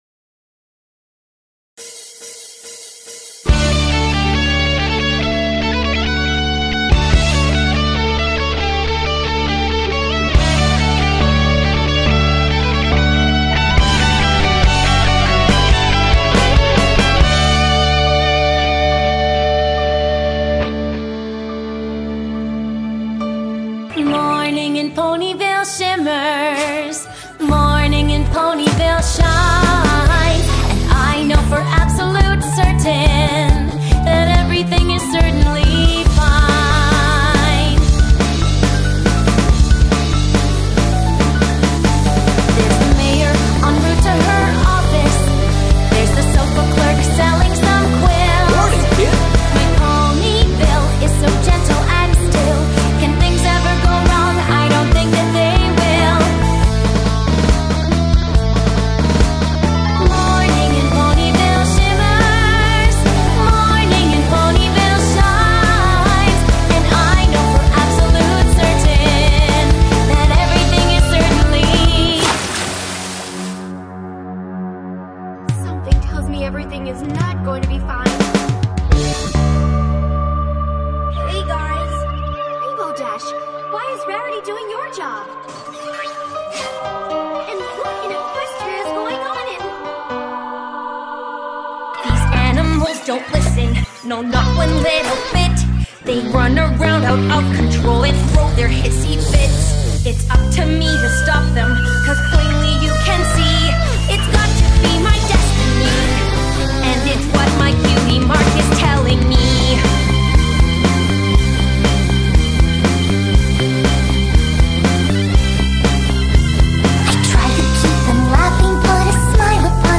7-Song Medley